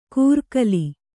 ♪ kūrkali